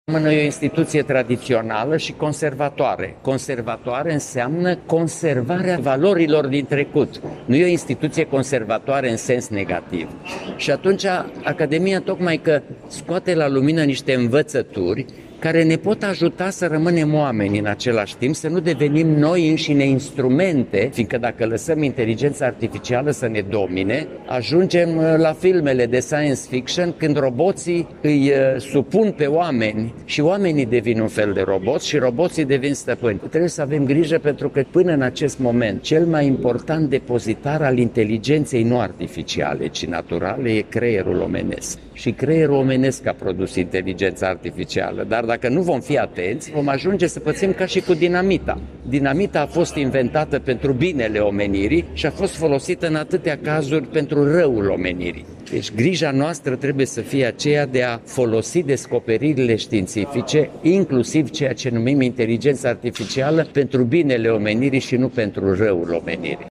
Președintele Academiei Române, Ioan-Aurel Pop, a declarat astăzi, la Iași, în cadrul unei prelegeri susținute înaintea Zilelor Academice Ieșene, că societatea trebuie să manifeste prudență în raport cu evoluția inteligenței artificiale, pentru ca aceasta să nu ajungă să domine ființa umană.